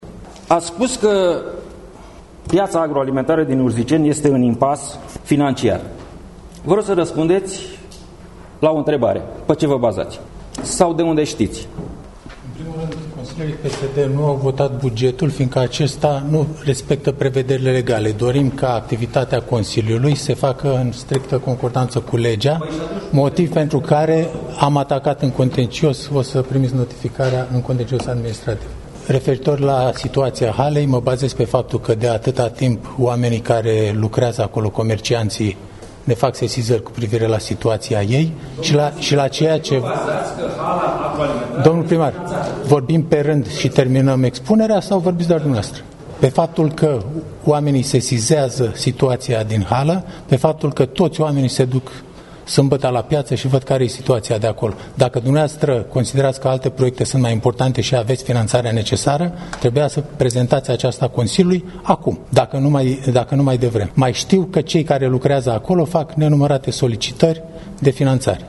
Primarul Constantin Sava a încercat să amendeze proiectul de hotărâre prin includerea în asocierea dintre muncipalitate şi Consiliul Judeţean a altor două obiective: finanţarea Spitalului Muncipal şi finalizarea lucrărilor la Baza de înot şi agrement. Edilul- şef al Urziceniului în dialog cu liderul de grup al social-democraţilor, Liviu Cazan: